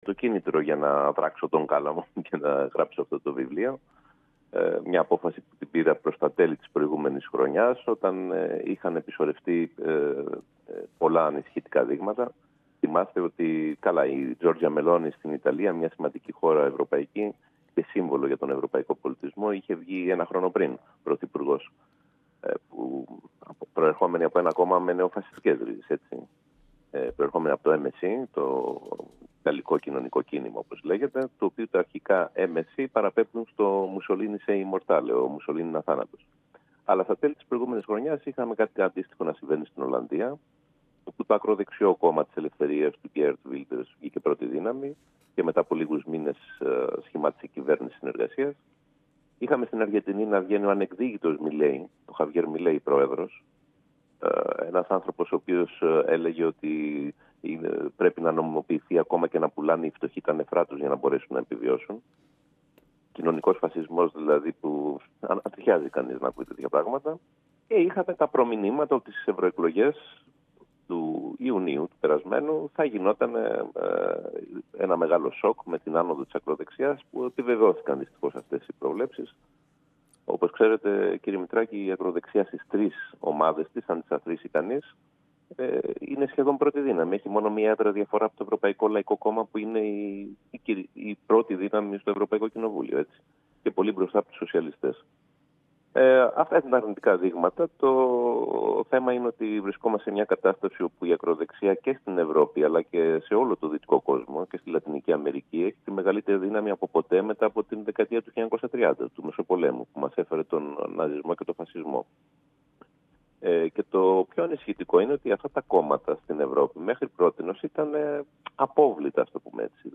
Οι Εκδόσεις Τόπος διοργάνωσαν την Τετάρτη 25 Σεπτεμβρίου, 2024 στην ΕΣΗΕΑ, (Ακαδημίας 20, αίθουσα «Γ. Καράντζας») την παρουσίαση του βιβλίου...